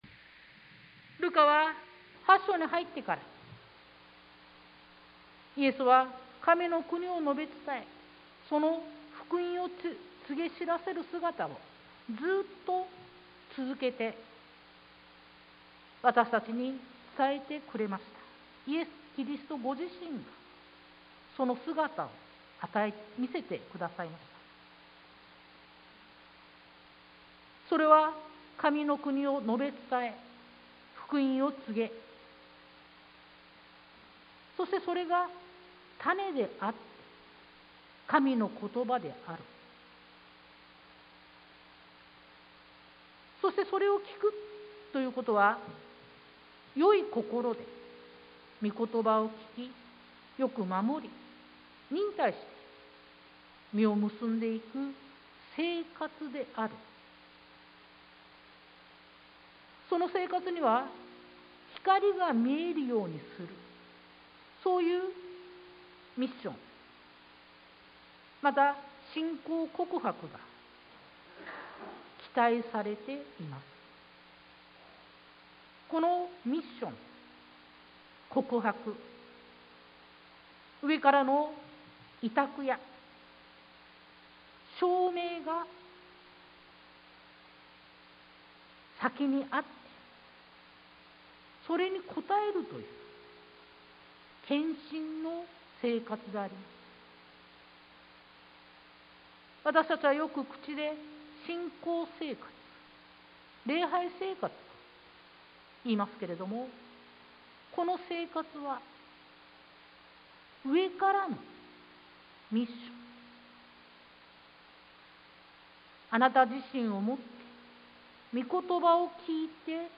sermon-2023-02-19